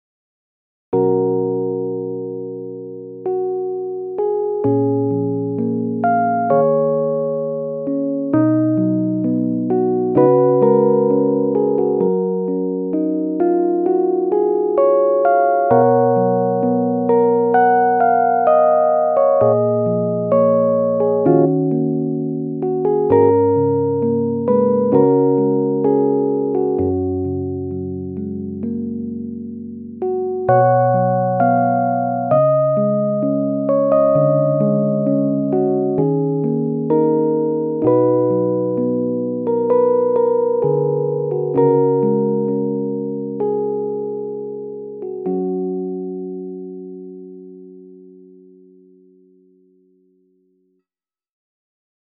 未分類 バラード 小さい頃の思い出 懐かしい 穏やか 青春 音楽日記 よかったらシェアしてね！